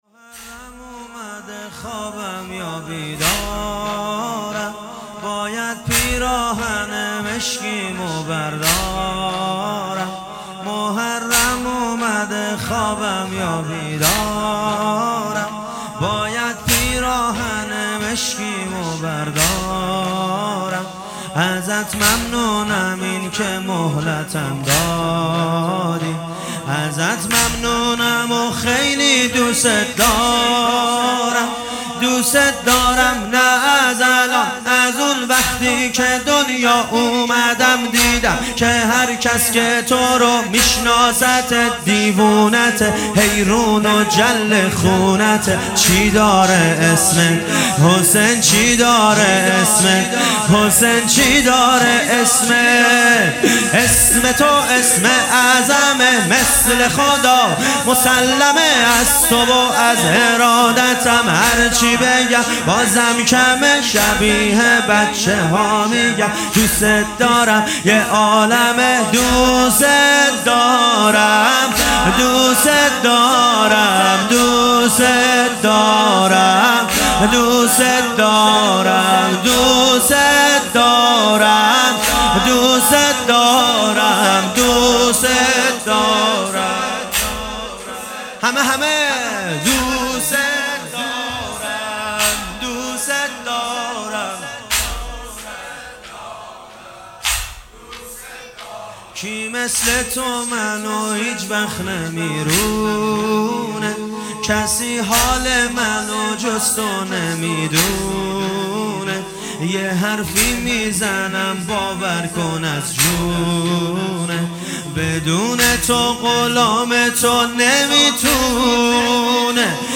محرم1400 - شب دوم - واحد - محرم اومده خوابم یا بیدار